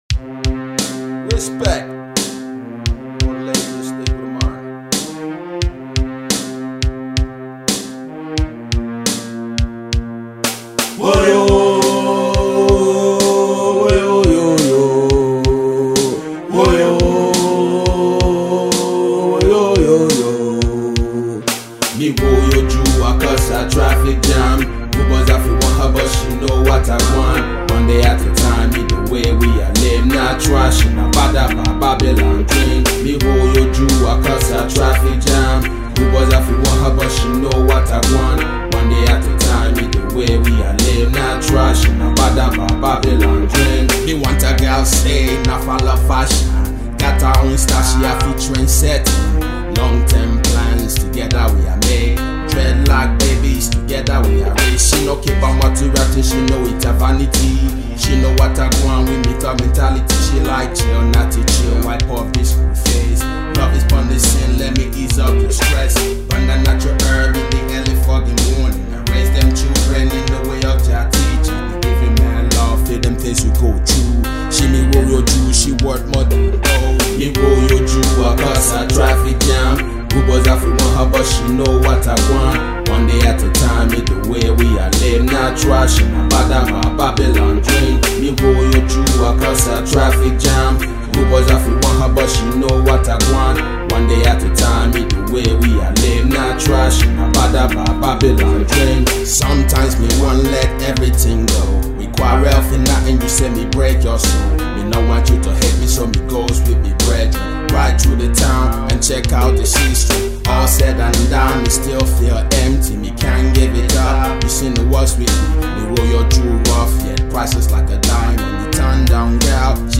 dance/electronic
Drum & bass
Hardcore